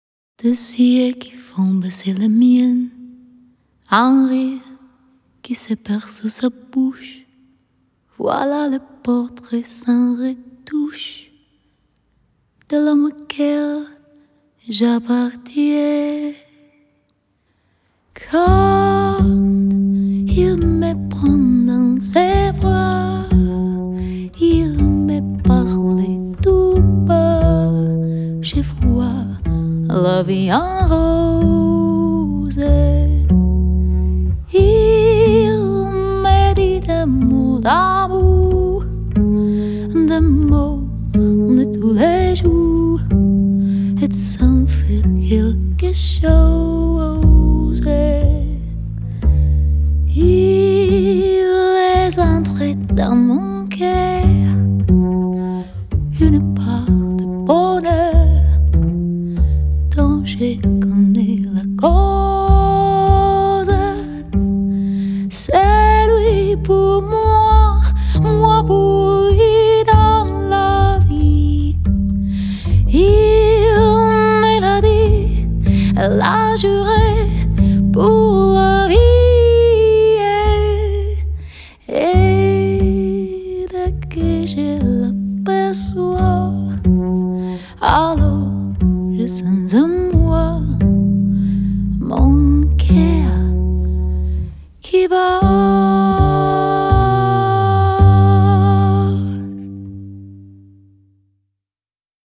Жанр: Jazz/Pop.